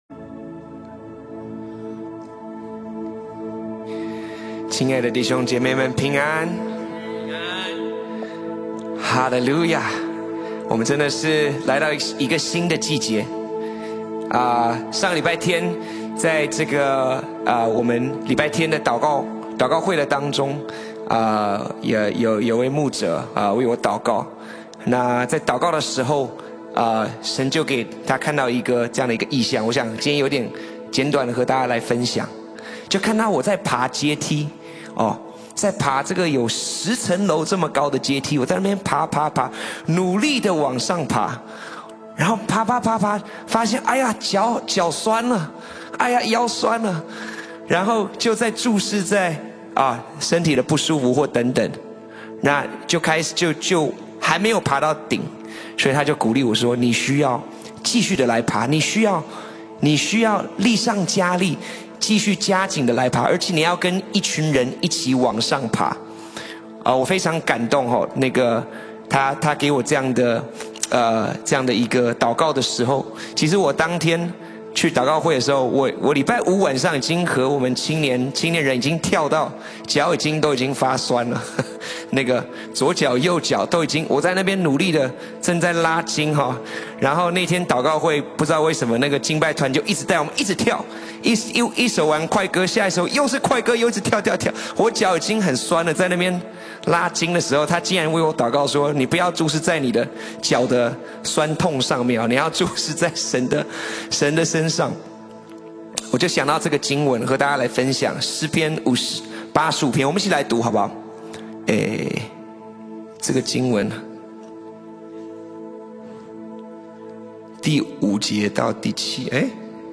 FRCC｜2024_11_23 主日敬拜_敬拜赞美